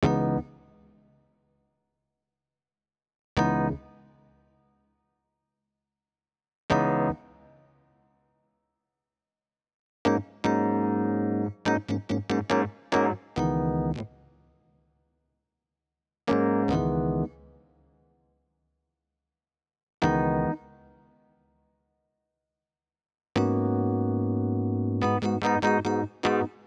05 rhodes A.wav